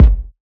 Storch Kick 2.wav